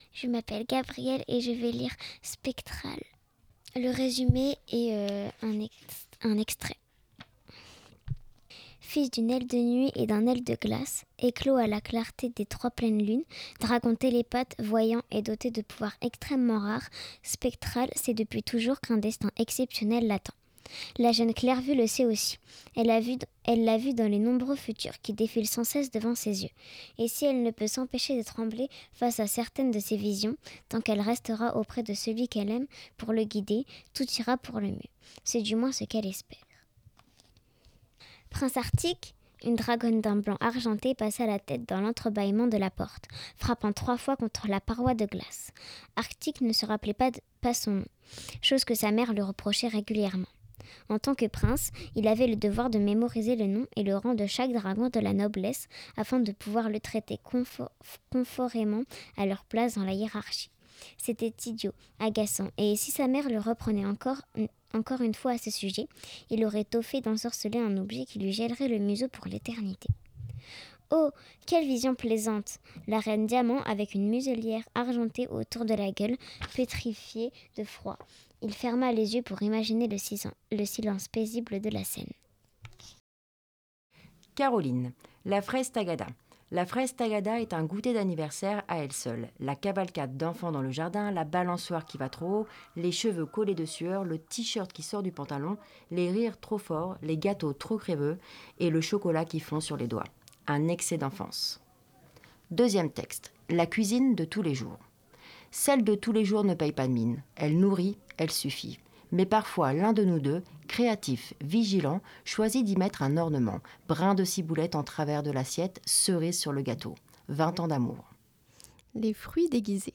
[15 ANS CHEZ CITE CARTER] [DE PAGES EN ONDES] Lecture sensible à voix haute (part. 2) - Radio Campus Amiens - 87.7 FM
Ce samedi 17 tout l’après midi, l’association Les Liserons Lisent, en coopération avec la Bibliothèque Bernheim, a proposé aux visiteurs de choisir un texte court, de s’entraîner un moment, puis de l’enregistrer à voix haute.